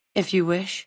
femaleadult06